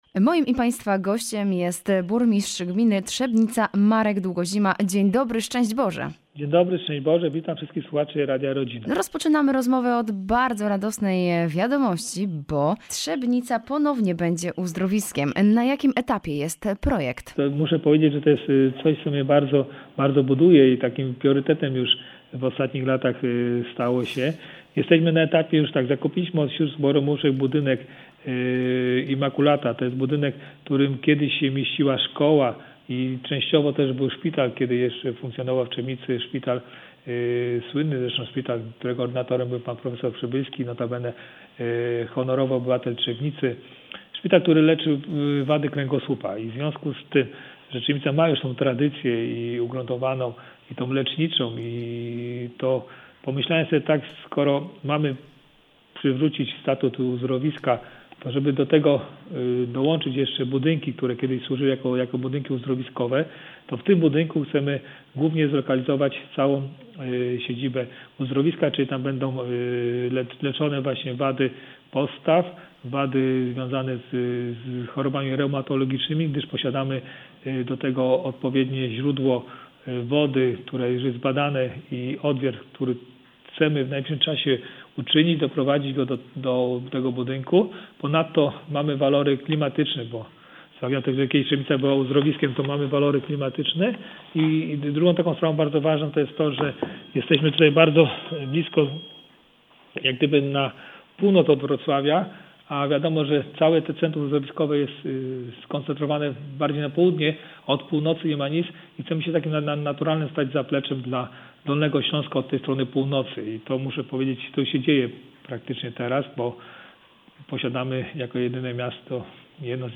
01_rozmowa-z-burmistrzem-Trzebnica.mp3